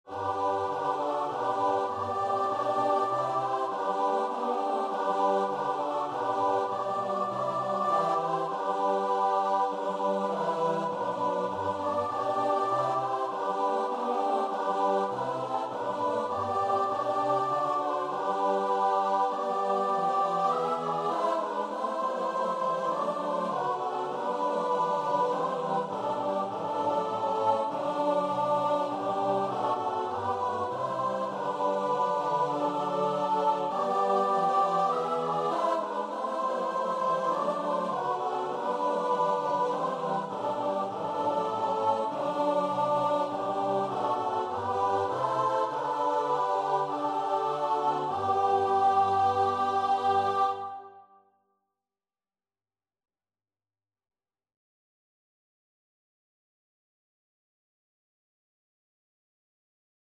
Christmas
Free Sheet music for Choir (SATB)
4/4 (View more 4/4 Music)